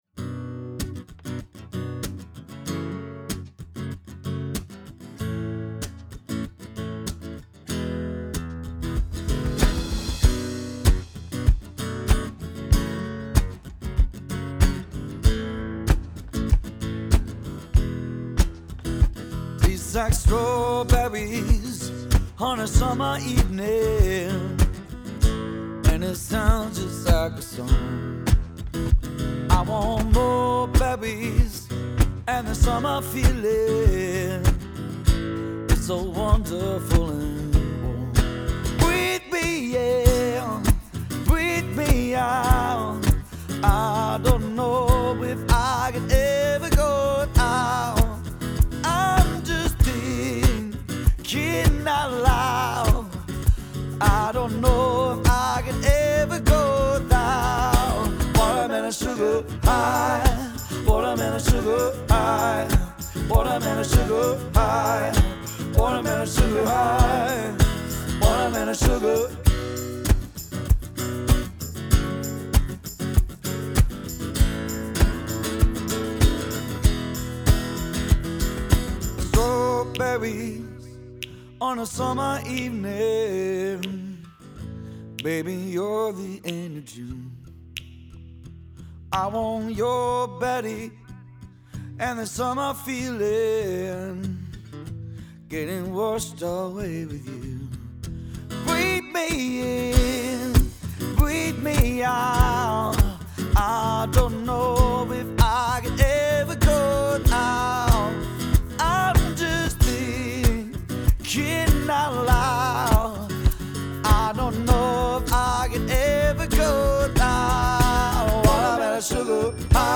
Dual Vocals | Guitar |Cajon/Percusion